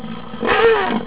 c_rhino_atk2.wav